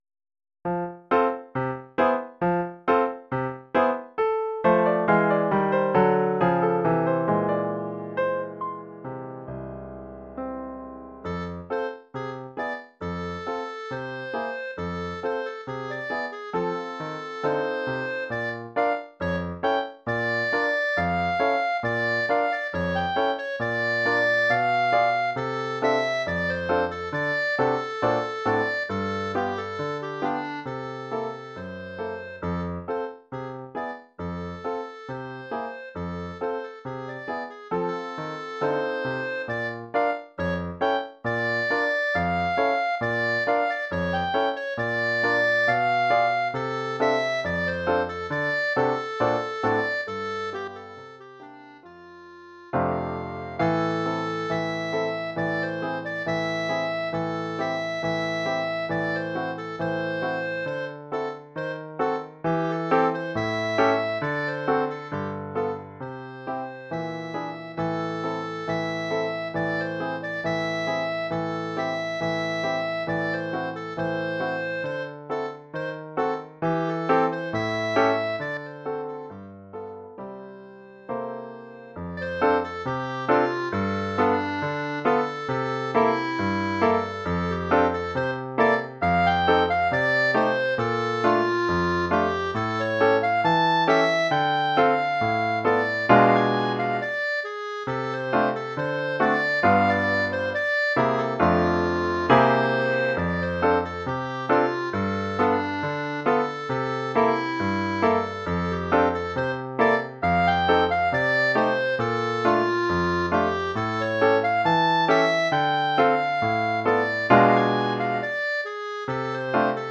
Oeuvre pour clarinette et piano.